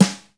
56BRUSHSD1-L.wav